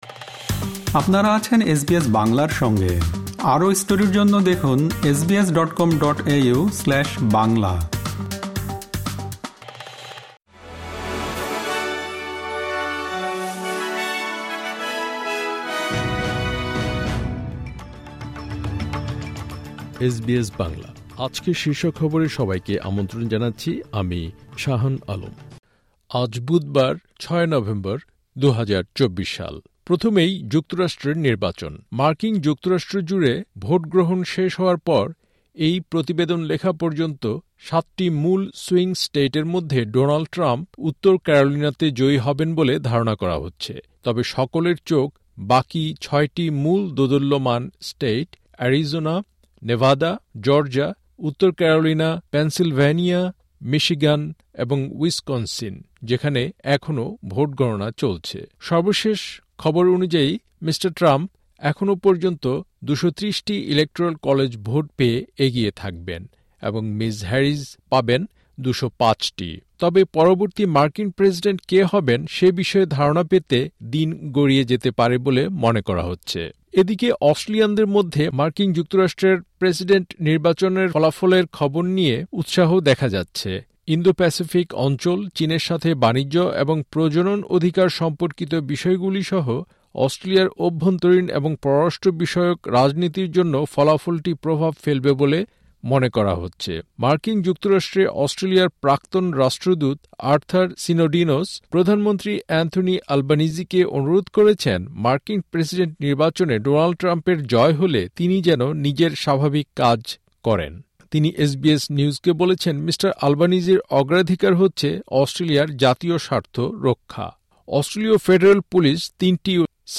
এসবিএস বাংলা শীর্ষ খবর: ৬ নভেম্বর, ২০২৪